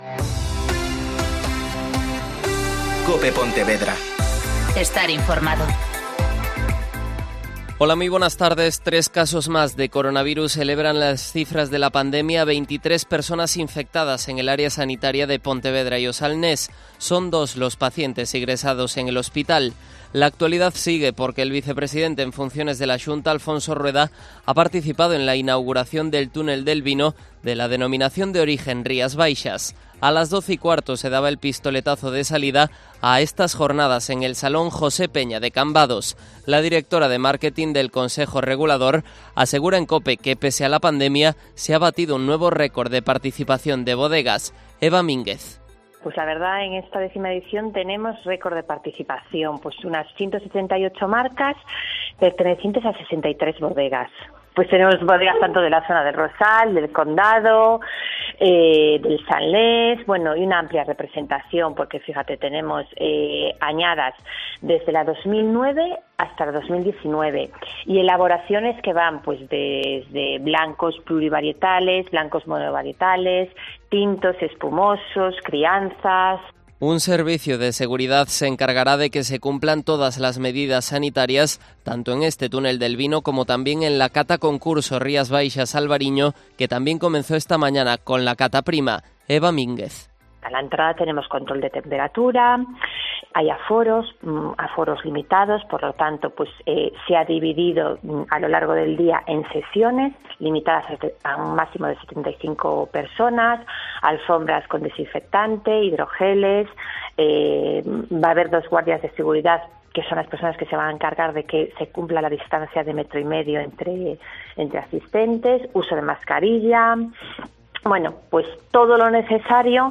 Mediodía COPE Pontevedra (Informativo 14,20h)